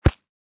Body armor 4.wav